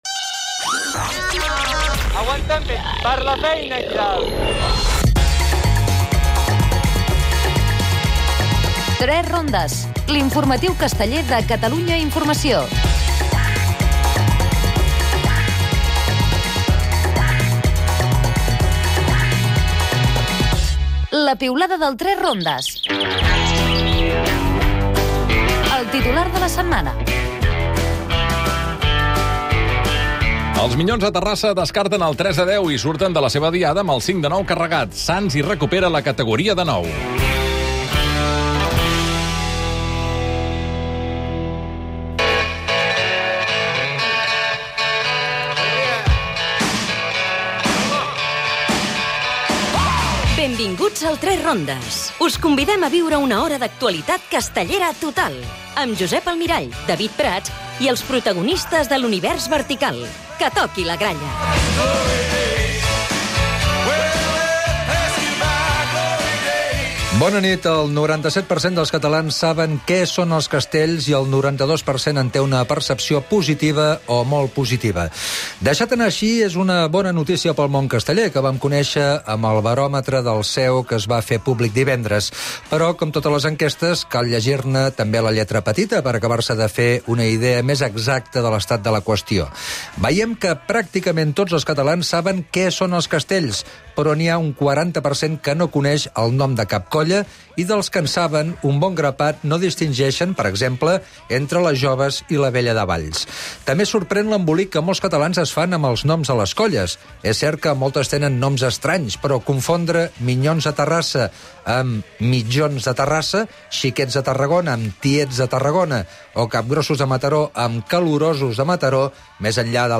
"3 rondes" ha estat a la diada dels Minyons de Terrassa. El viatge a Mxic dels Castellers de Vilafranca.